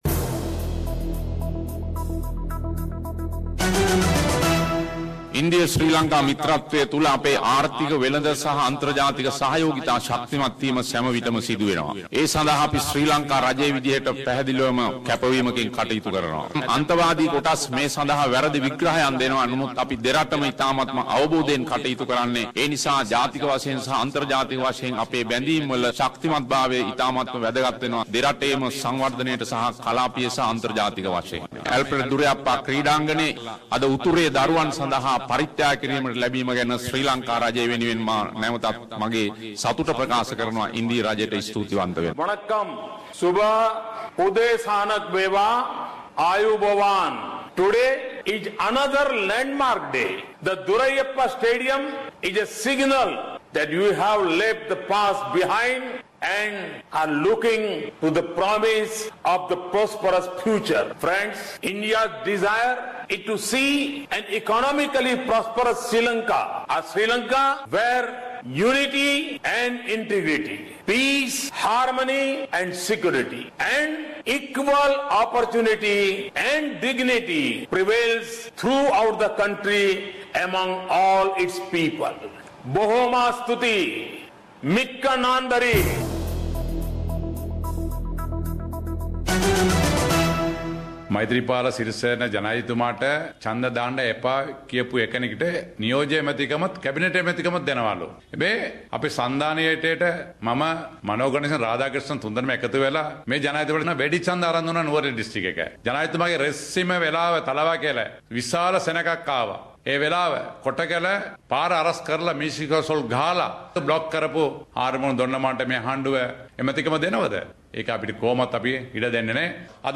Weekly Sinhala news wrap